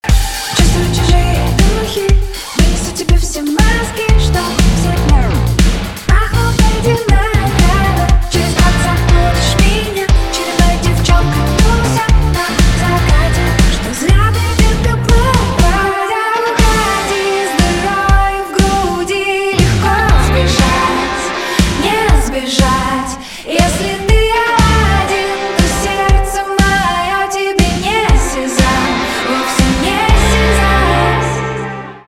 поп
гитара , барабаны
грустные